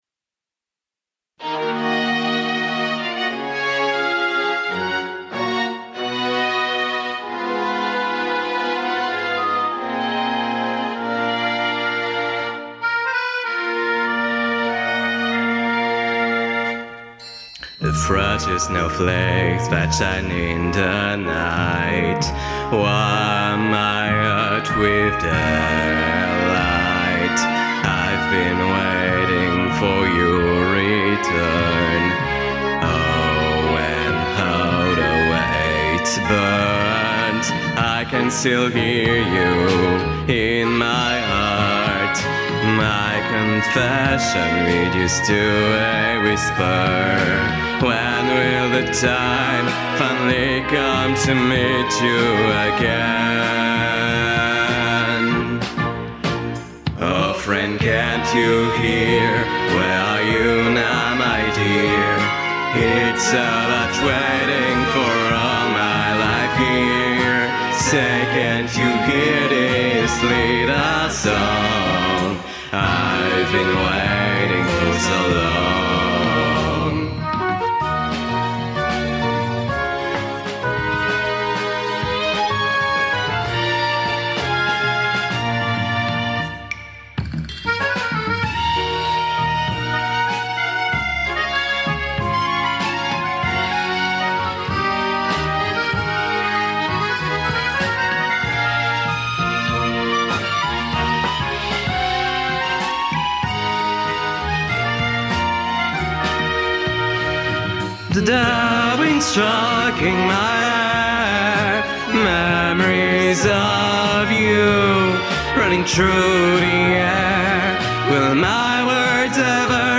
Eb Andante